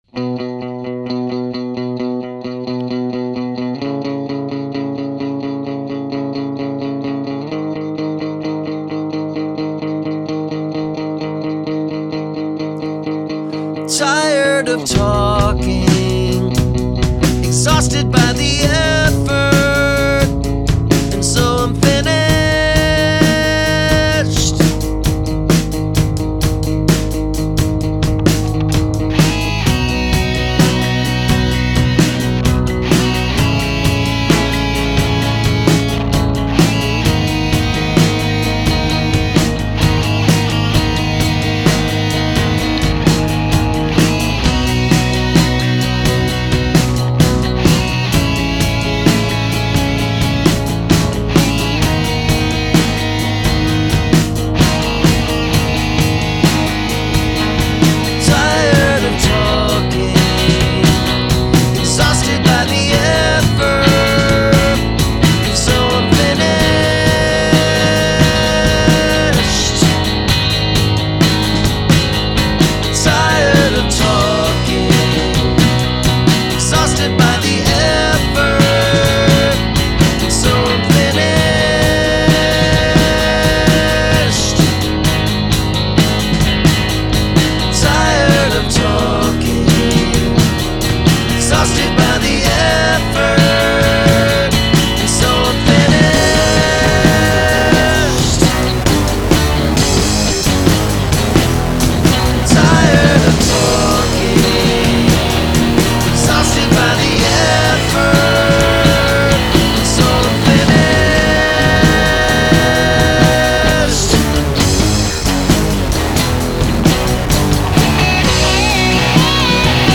Lyric consists of only one sentence.